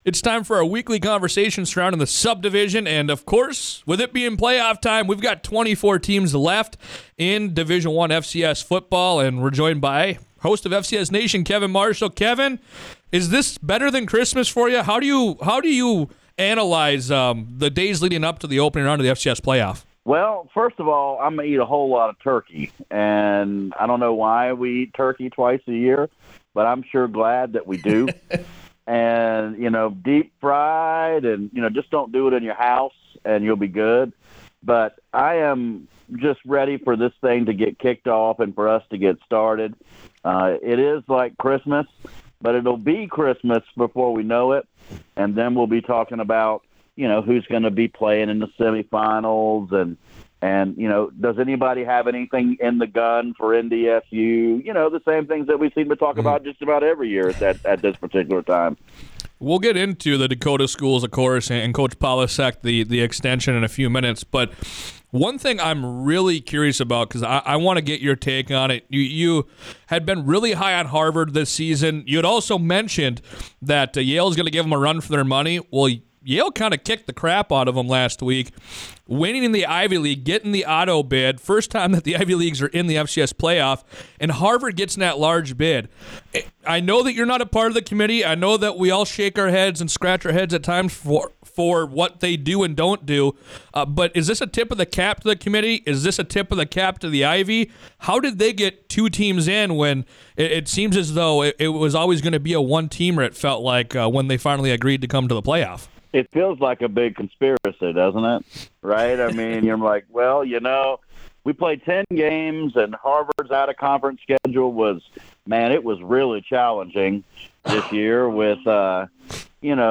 for their weekly conversation surrounding the subdivision. They previewed the FCS playoffs, discussed Tim Polasek’s extension with NDSU, and more.